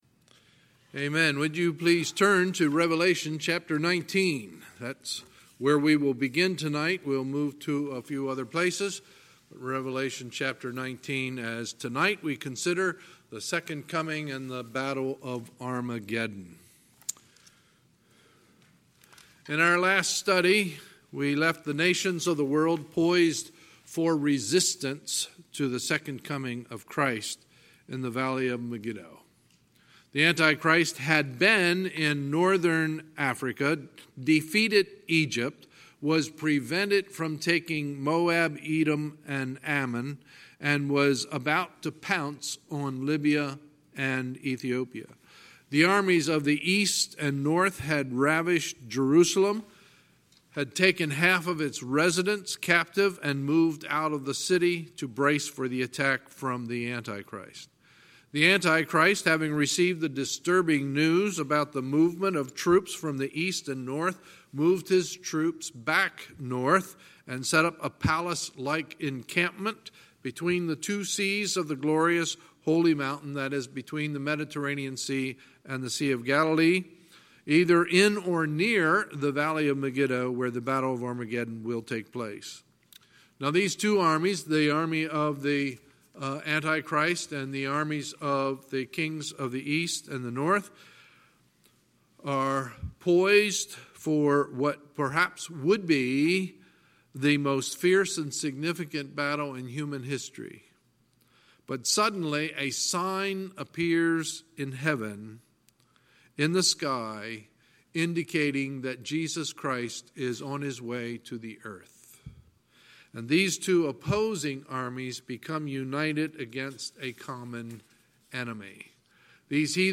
Sunday, July 21, 2019 – Sunday Evening Service